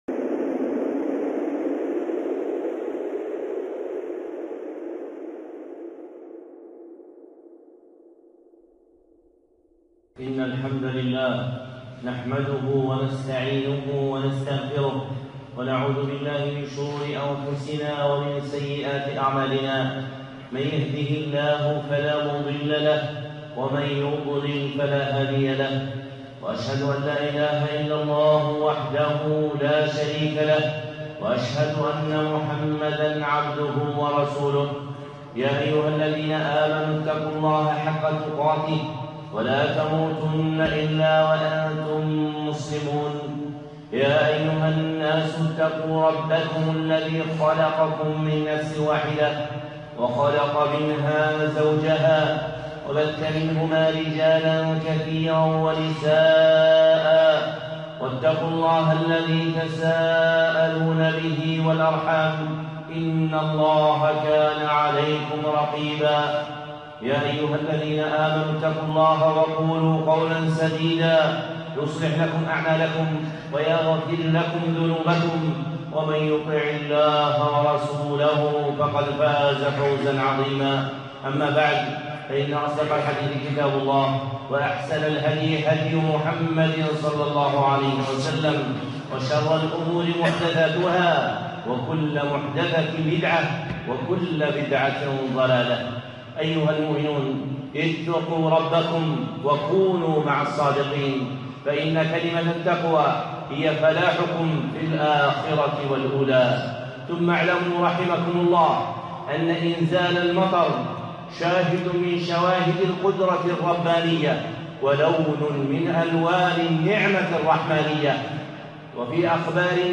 خطبة (المطر… بين المؤمن والكافر)